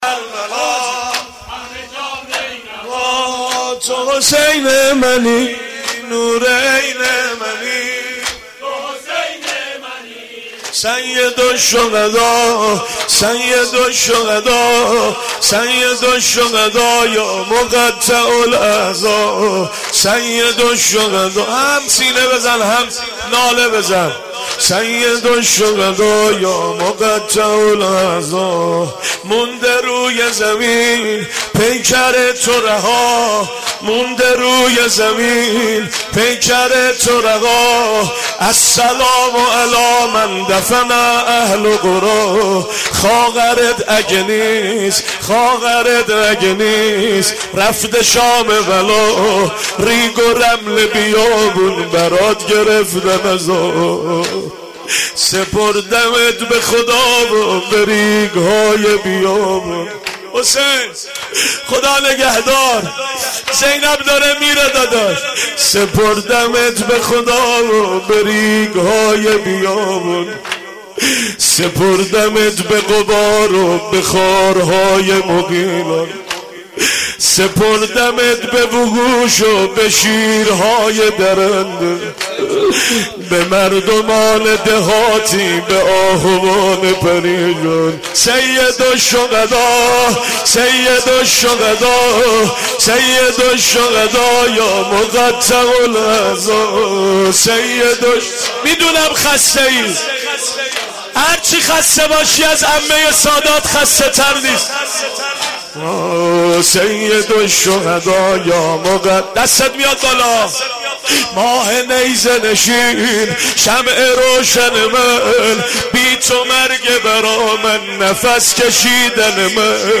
مداحی
هیئت حضرت رقیه س (نازی آباد)